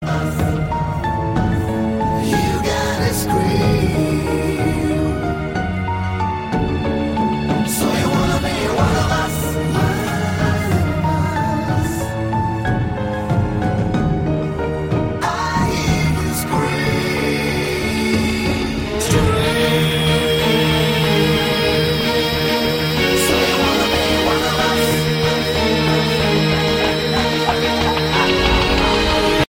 Category: Melodic Hard Rock
guitar
vocals
drums
bass
keyboards